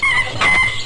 Chimp Sound Effect
chimp.mp3